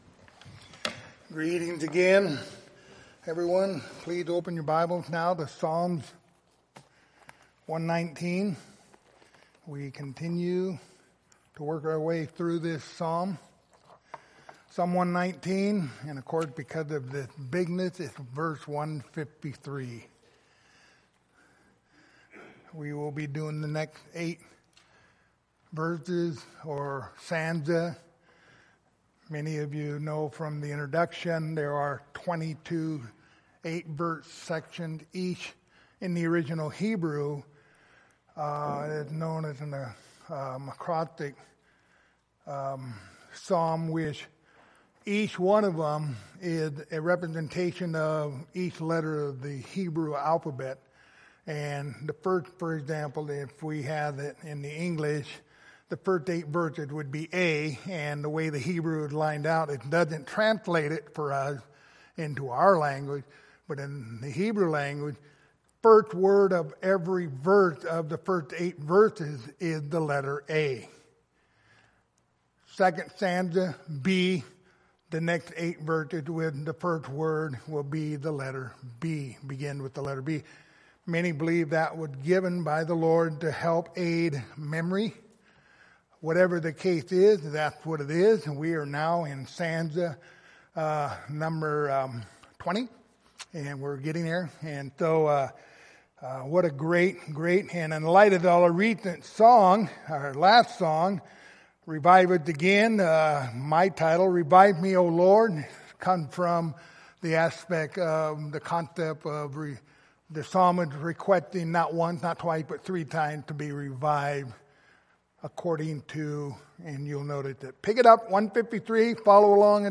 Passage: Psalm 119:153-160 Service Type: Sunday Morning Topics